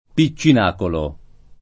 piccinaco